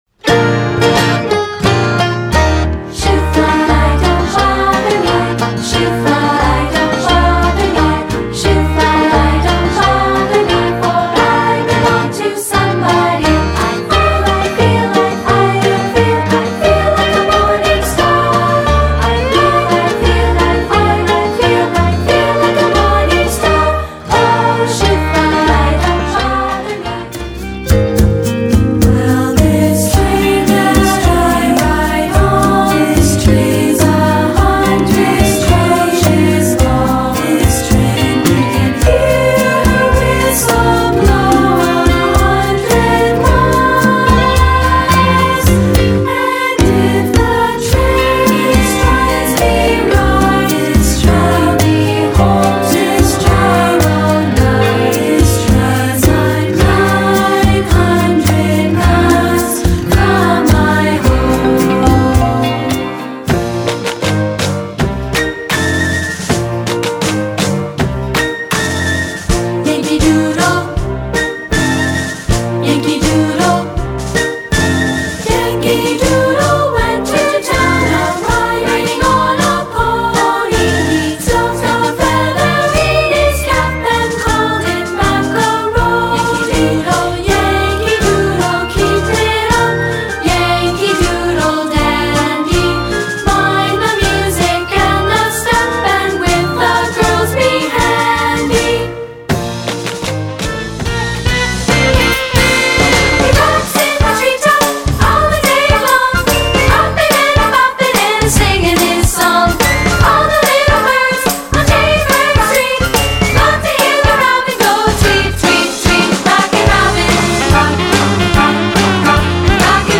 General Music Song Collections Celebrate America General